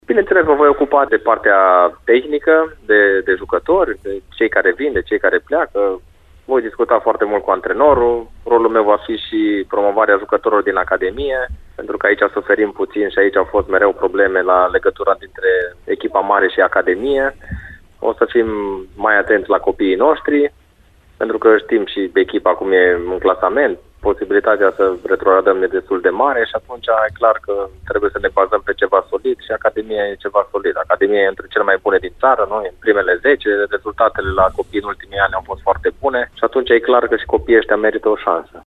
Noul director sportiv al divizionarei secunde de fotbal Politehnica Timișoara, Paul Codrea, a fost invitatul ediției de sâmbătă a emisiunii Arena Radio.